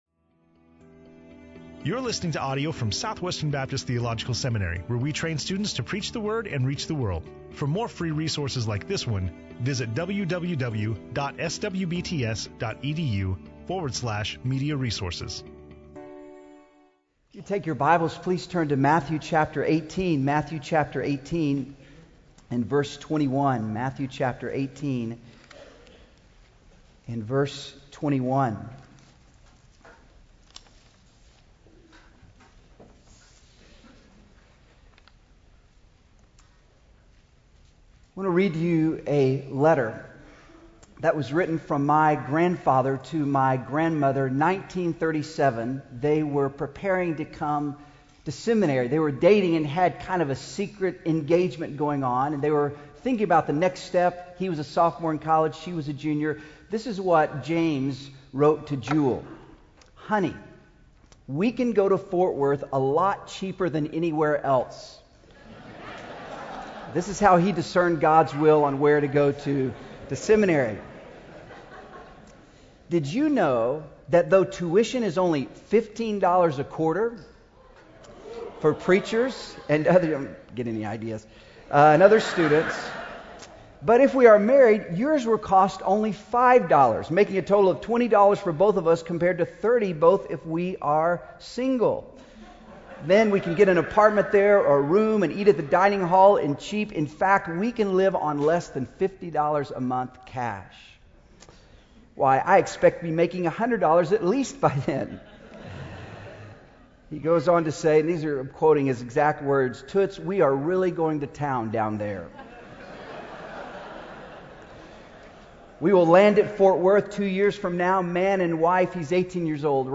Matthew 18:21-35 in SWBTS Chapel